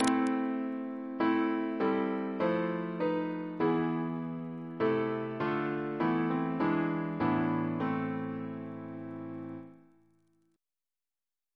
Single chant in A minor Composer: Sir H. Walford Davies (1869-1941), Organist of the Temple Church and St. George's, Windsor Reference psalters: ACP: 113; RSCM: 150 214